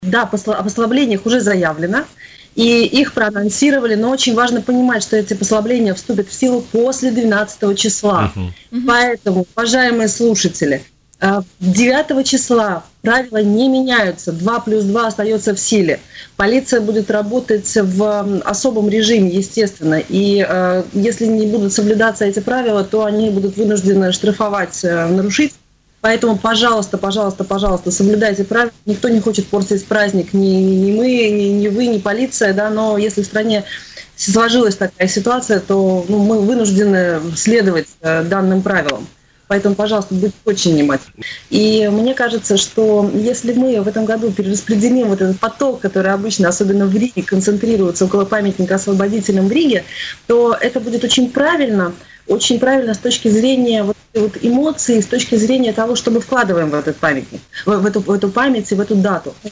В интервью она подчеркнула, что в связи с чрезвычайной ситуации в стране, массовые торжественные мероприятия по случаю празднования 75-летней годовщины Великой Победы у памятника Освободителем Риги по-прежнему отменены.